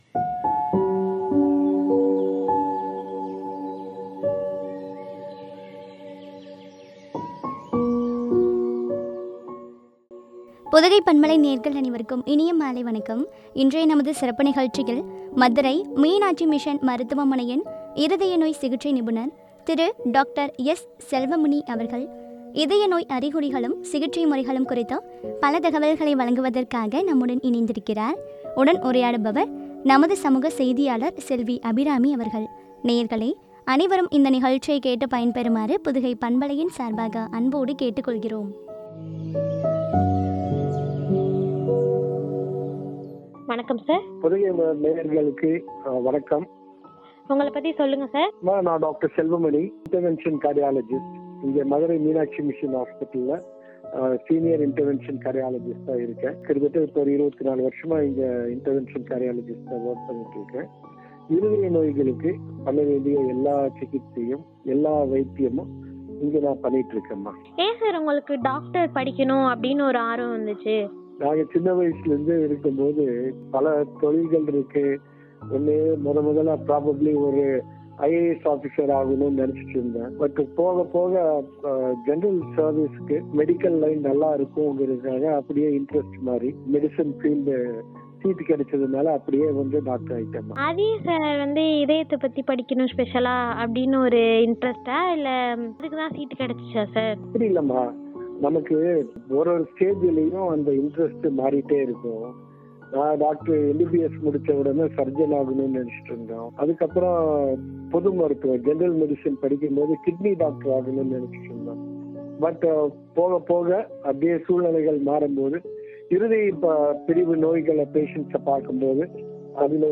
சிகிச்சை முறைகளும் பற்றிய உரையாடல்.